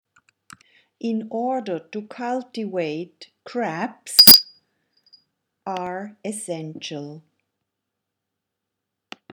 The sound of the bell tells you where a word/expression is missing .